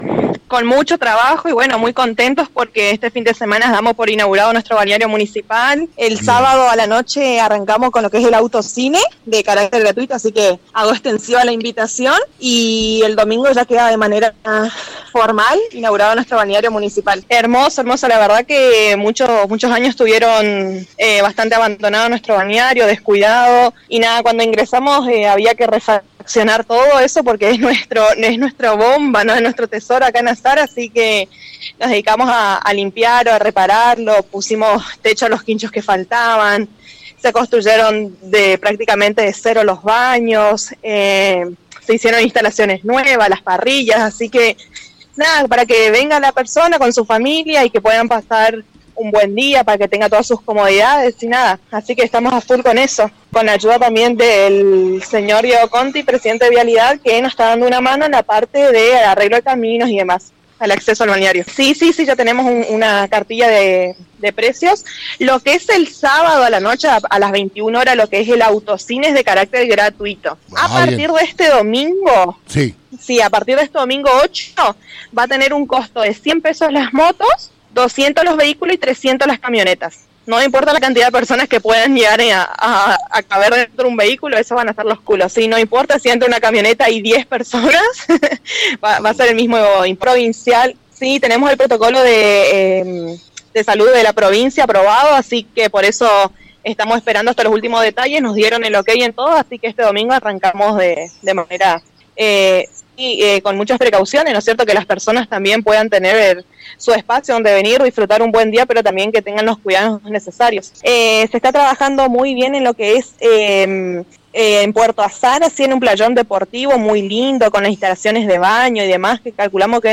En charla telefónica con la Concejal Sandra Teleban en el programa Infonews, que se emite por FM Band News 89.7, comentaba como se viene trabajando en la localidad de Azara y la alegría que conlleva la reapertura de Balneario Municipal donde se realizaron muchas mejoras en el acceso y dentro del predio debido a que el lugar atrae a muchos visitantes de pueblos vecinos y las condiciones que se encontraba era deplorable.